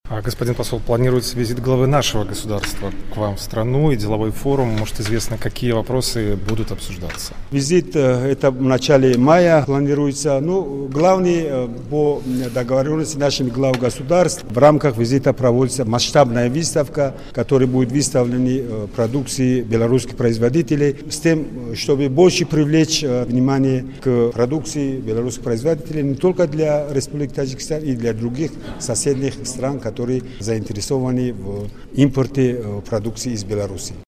Посол Таджикистана в Беларуси Махмадшариф Хакдод (о визите)